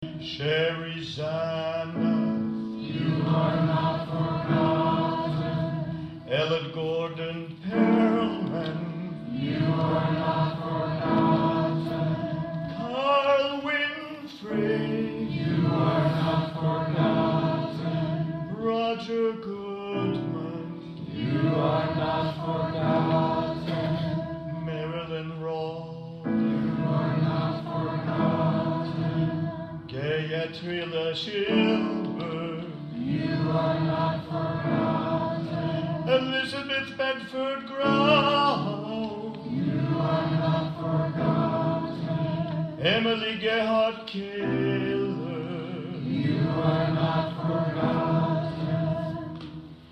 memservice2018.mp3